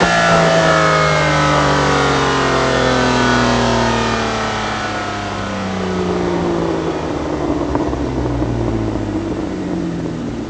rr3-assets/files/.depot/audio/Vehicles/v12_01/v12_01_Decel.wav
v12_01_Decel.wav